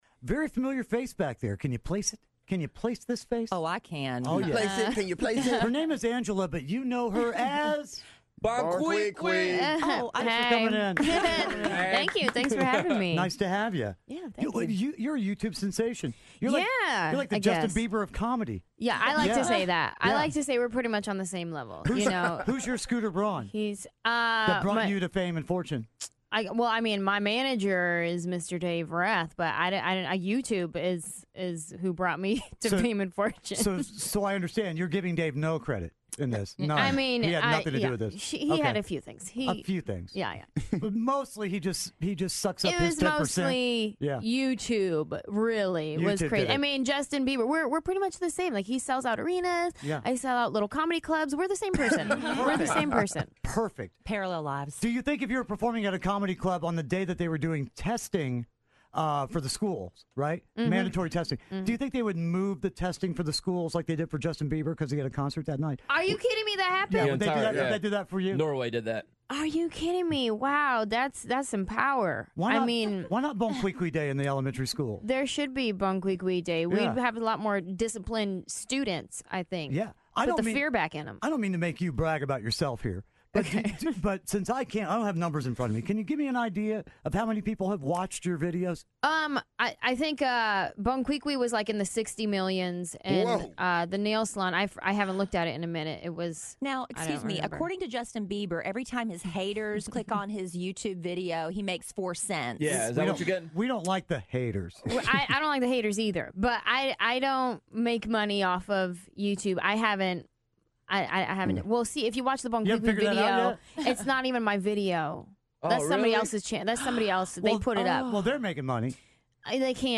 Anjelah Johnson Interview
Kidd Kraddick in the Morning interviews Anjelah Johnson, a.k.a. Bon Qui Qui.